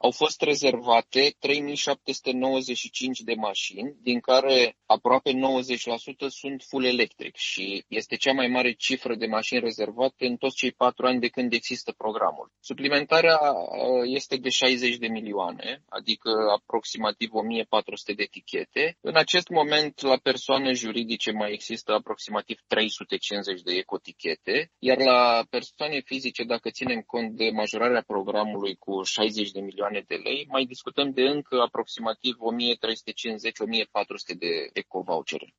Secretarul de stat în Ministerul Mediului, Mircea Fechet: